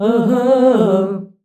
AHAAH C.wav